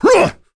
Gau-Vox_Attack1.wav